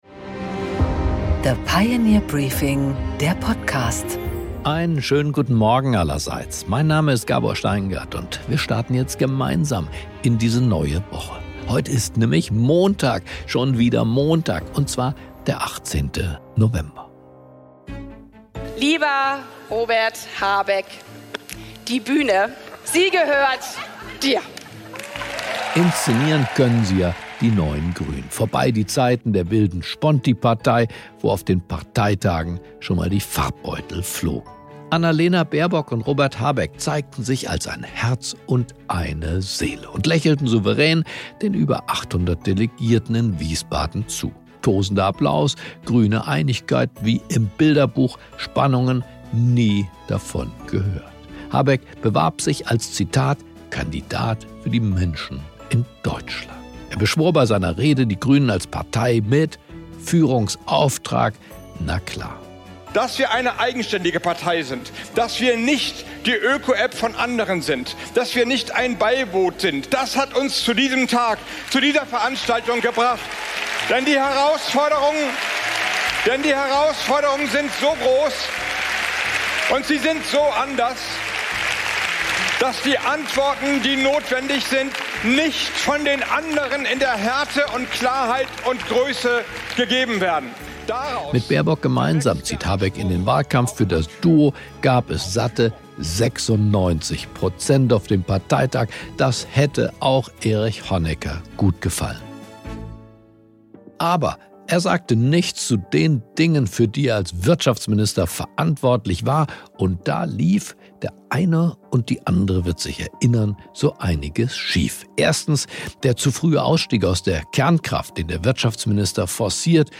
Im Interview: Werner Gatzer , Vorsitzender des Aufsichtsrats der Deutschen Bahn AG, spricht mit Gabor Steingart über die Deutsche Bahn, die Probleme mit der Infrastruktur und die mangelhafte Pünktlichkeit.
Gabor Steingart präsentiert das Pioneer Briefing